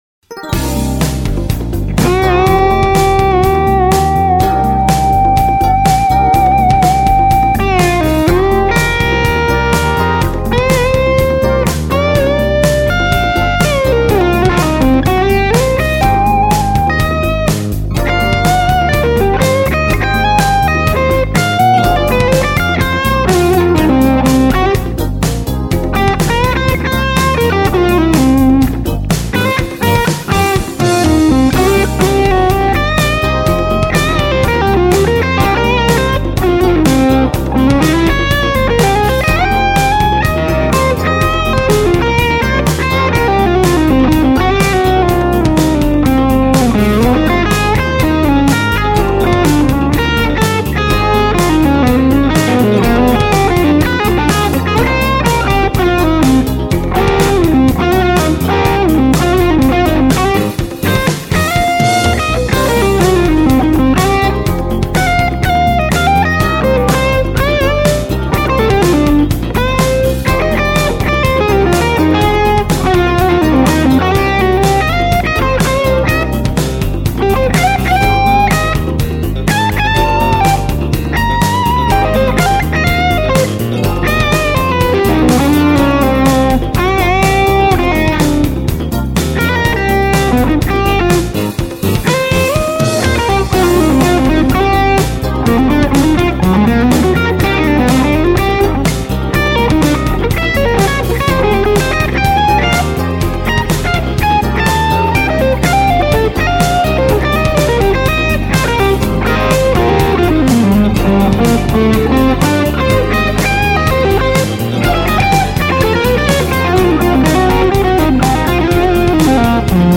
Just for giggles, I just recorded these two clips to compare the tone of Fender iron to Music Man iron. Both amps were set to similar settings and gain levels.
Same mic positions.
No EQ at all. Just a touch of verb added. Same exact verb and levels between the two.
Clip one: Bludo Music Man 6L6 Skyline (recorded first)